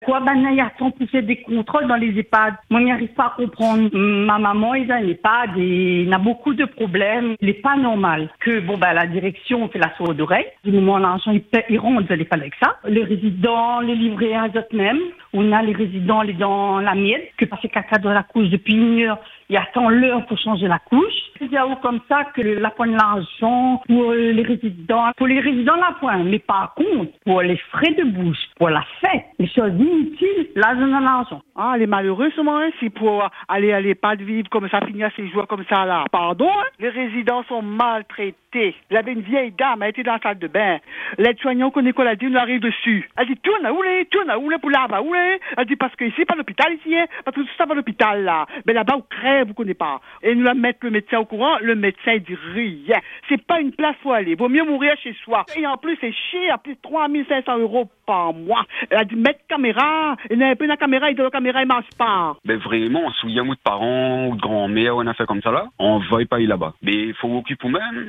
Auditeurs, familles, proches de résidents : vous avez raconté ce que vous voyez, ce que vous vivez.